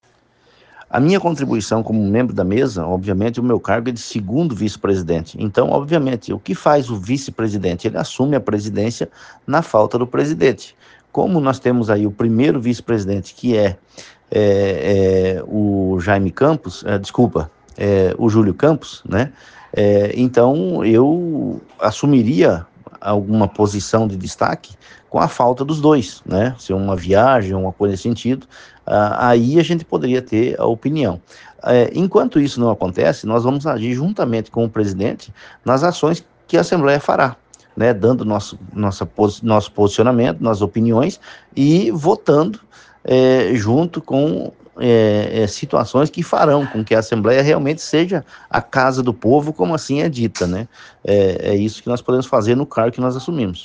OUÇA NA ÍNTEGRA A ENTREVISTA QUE O SITE OPINIÃO FEZ COM O DEPUTADO GILBERTO CATTANI